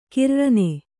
♪ kirrane